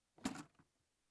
Open Case.wav